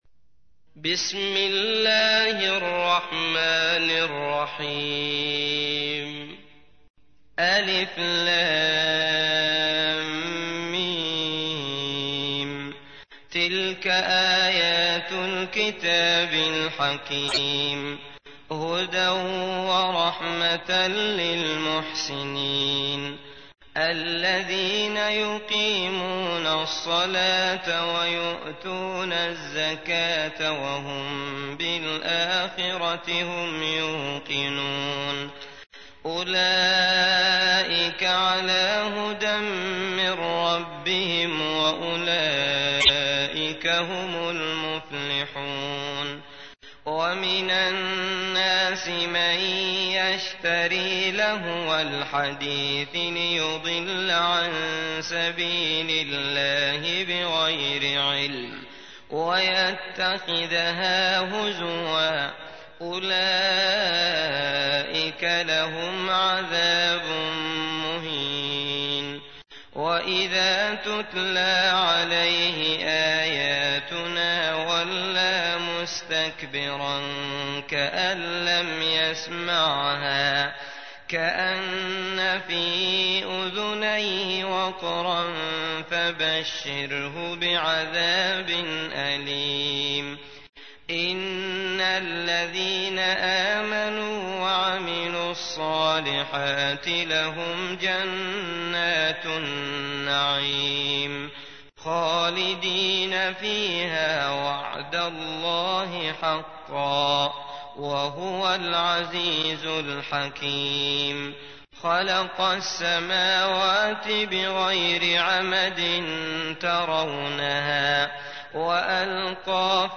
تحميل : 31. سورة لقمان / القارئ عبد الله المطرود / القرآن الكريم / موقع يا حسين